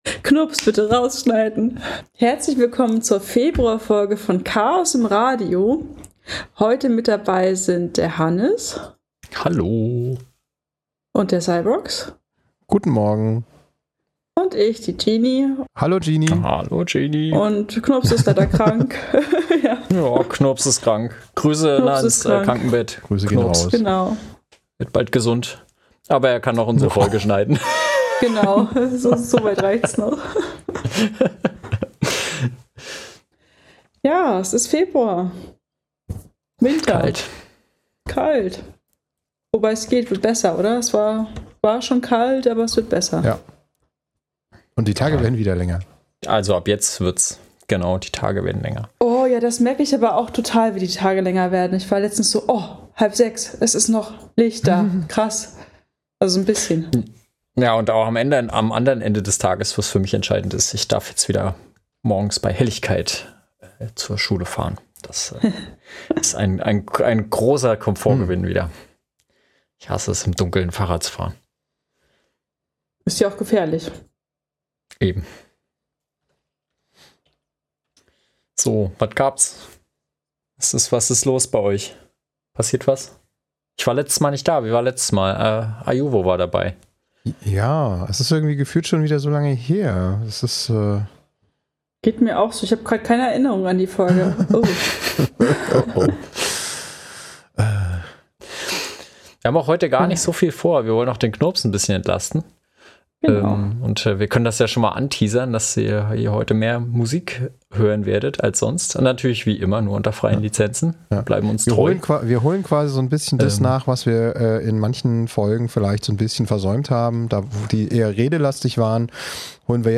reden über Heimautomatisierung und spielen Musik.